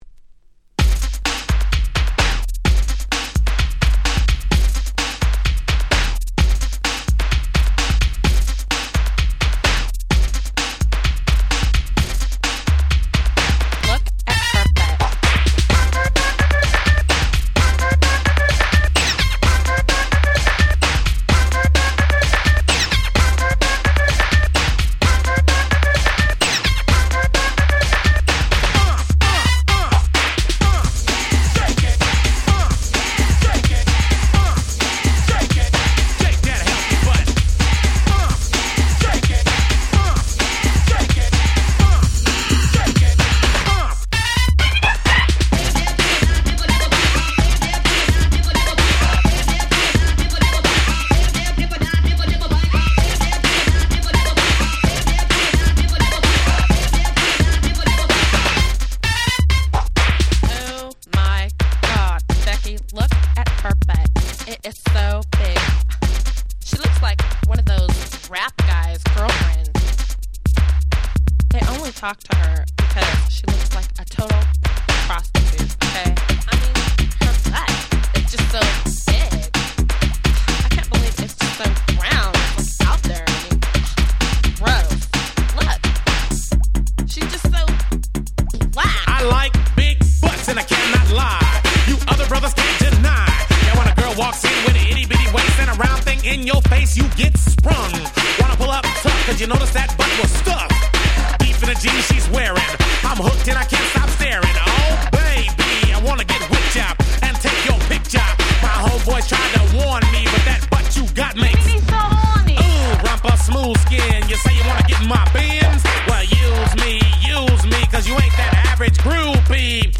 【Media】Vinyl 12'' Single
自分が当時好んで使用していた曲を試聴ファイルとして録音しておきました。